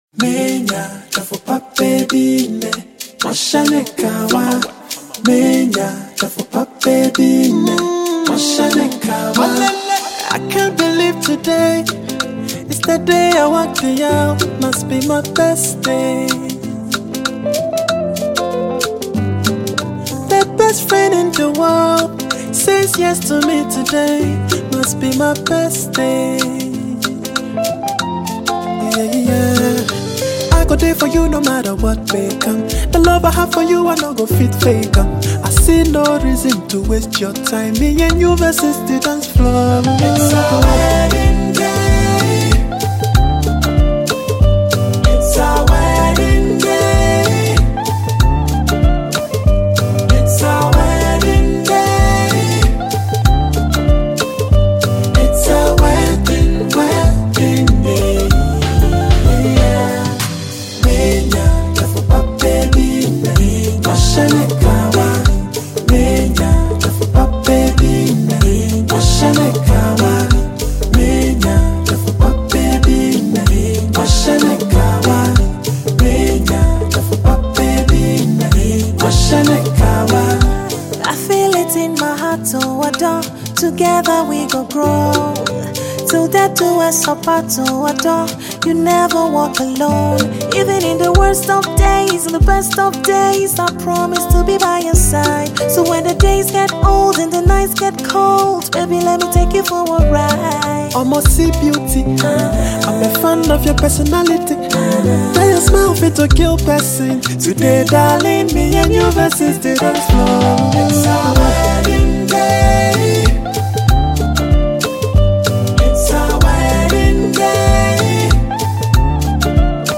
Ghanaian talented highlife afrobeat singer